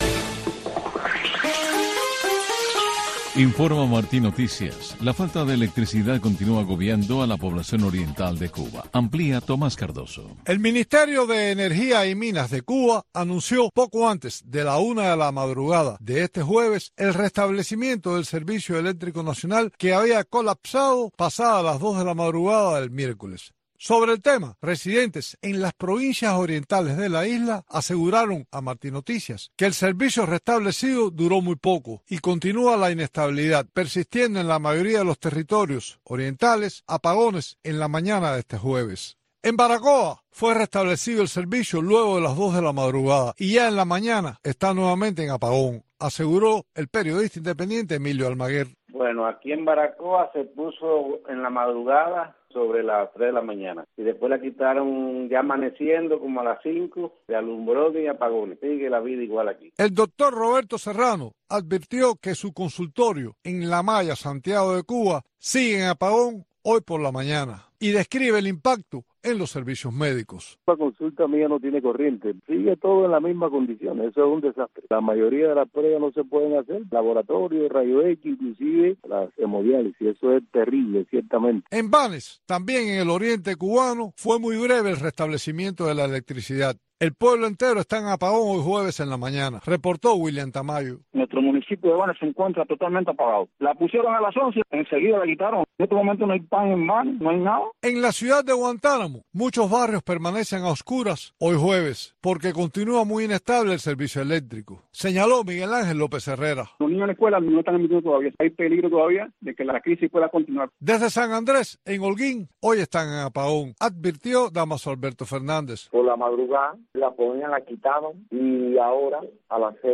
Una mirada a la situación migratoria, para analizar las leyes estadounidenses, conversar con abogados y protagonistas de este andar en busca de libertades y nuevas oportunidades para lograr una migración ordenada y segura.